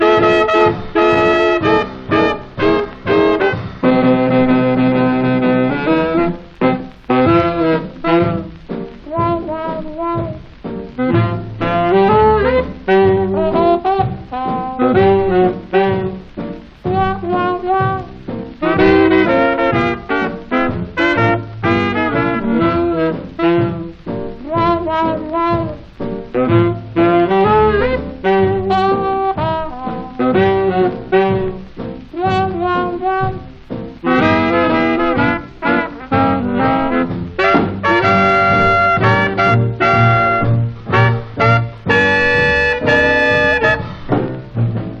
Jazz, Jump Blues, Swing　US　12inchレコード　33rpm　Mono